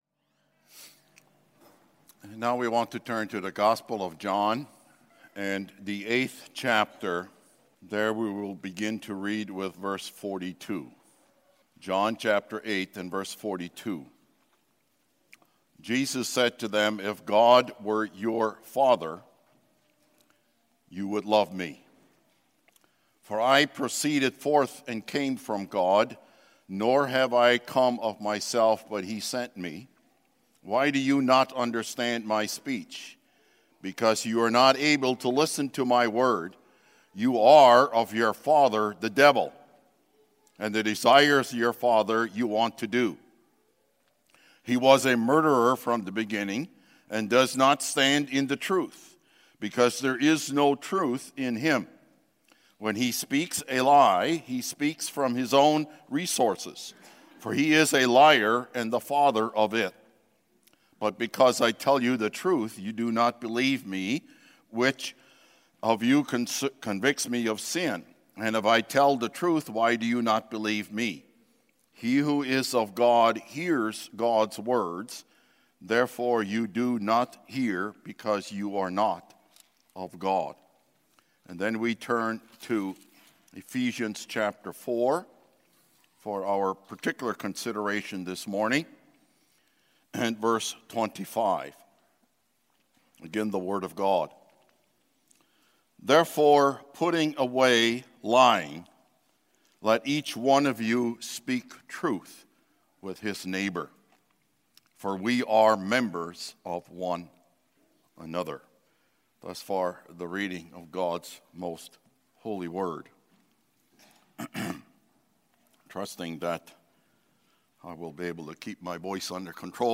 Speaking the Truth | SermonAudio Broadcaster is Live View the Live Stream Share this sermon Disabled by adblocker Copy URL Copied!